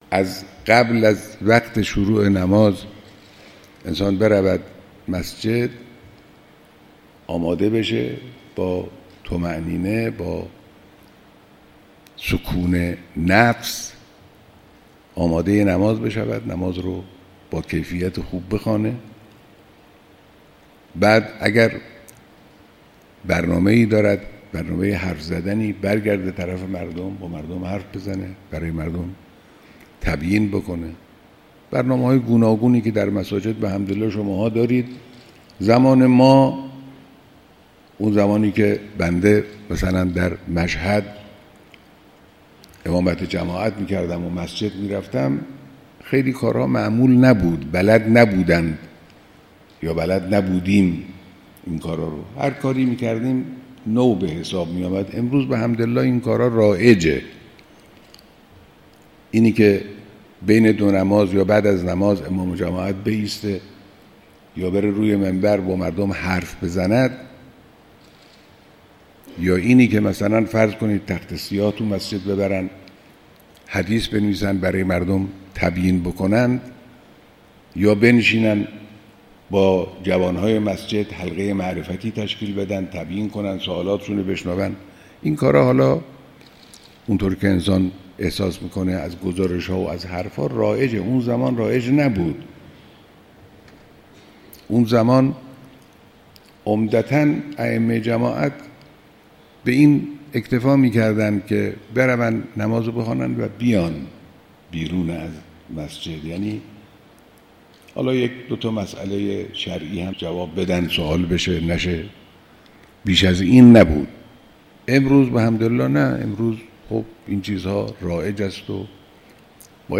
بیانات کوتاه حضرت امام خامنه‌ای(مدظله) در دیدار ائمه جماعات مساجد شهر تهران در روز جهانی مسجد پیرامون نماز